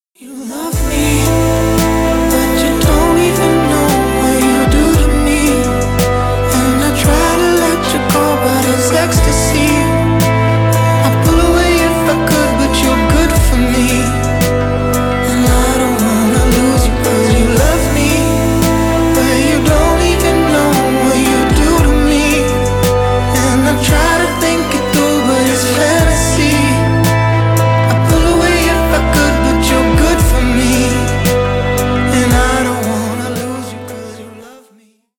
Рок Металл
грустные